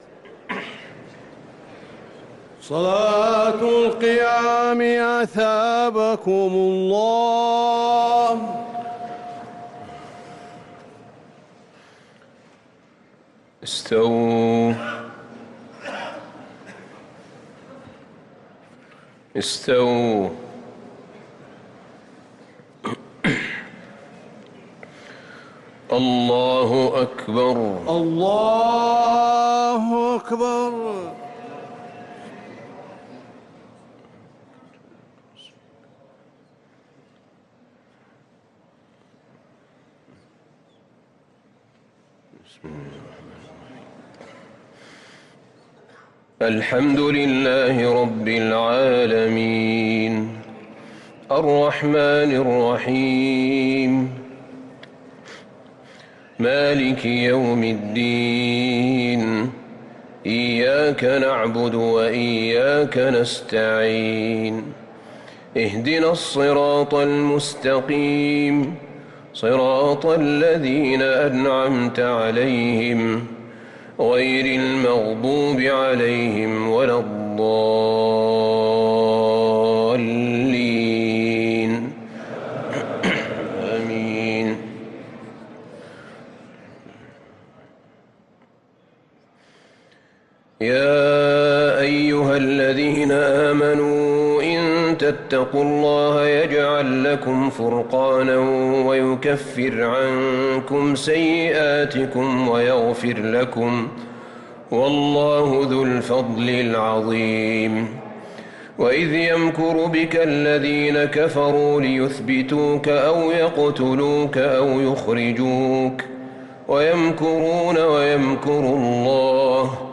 تراويح ليلة 13 رمضان 1444هـ من سورتي الأنفال (29-75) التوبة (1-22) |taraweeh 13st niqht Surah Al-Anfal and At-Tawba 1444H > تراويح الحرم النبوي عام 1444 🕌 > التراويح - تلاوات الحرمين